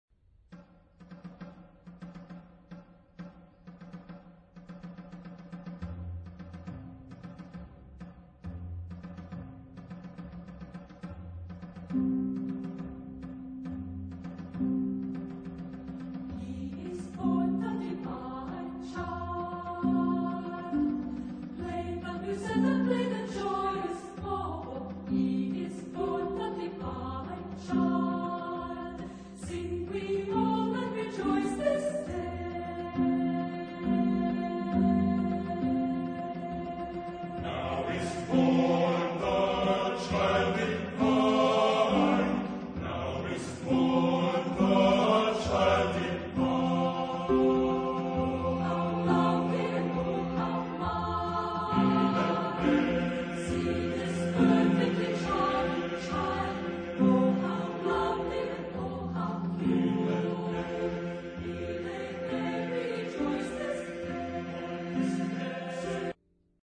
Genre-Style-Form: Carol ; Arrangement ; Bolero
Type of Choir: SATB divisi  (4 mixed voices )
Instruments: Harp (1) ; Double bass (1) ; Snare drum (1)
Tonality: F major